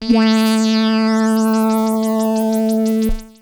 /audio/sounds/Extra Packs/musicradar-synth-samples/Roland S100/Roland F Multi/
Roland F A3.wav